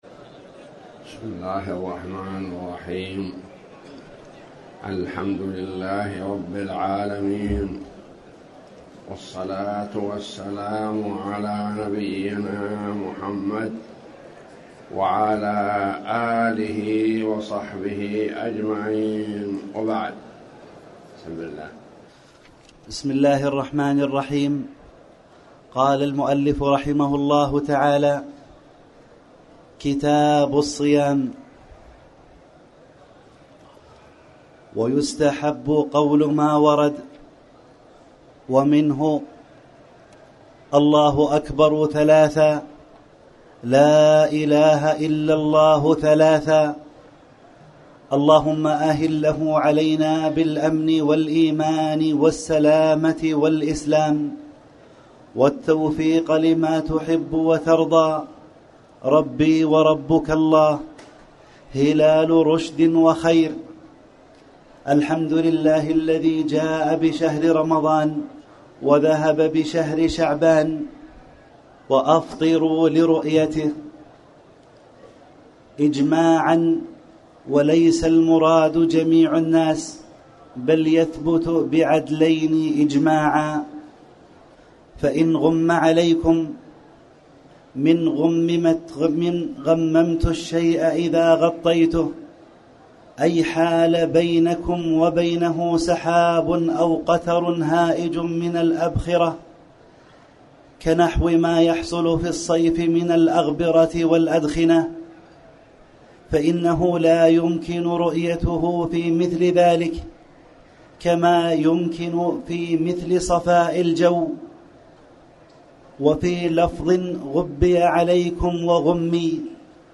تاريخ النشر ٤ رمضان ١٤٣٩ هـ المكان: المسجد الحرام الشيخ